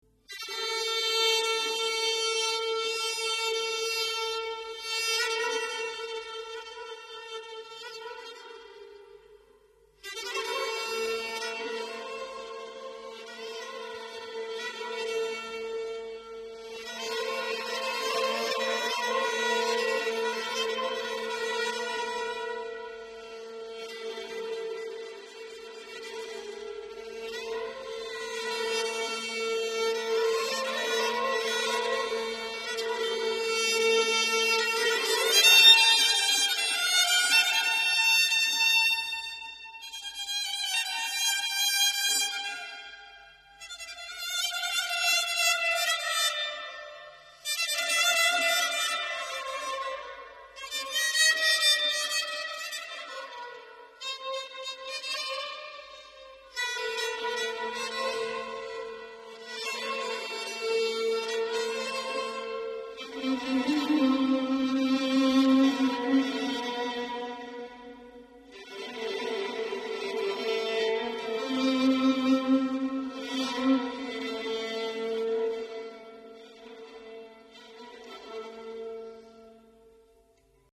Saz Va Avaz 5